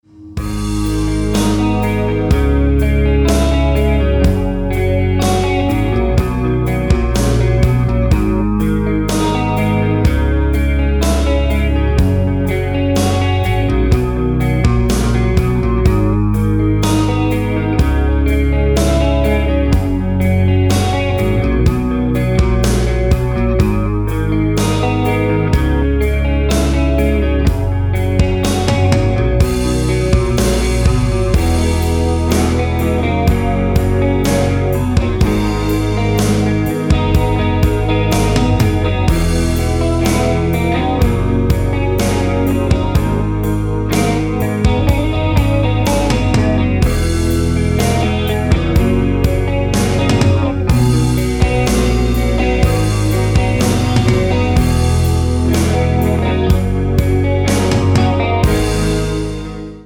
Instrumental
Рок рингтоны